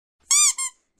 Duck toy sound Meme sound effects free download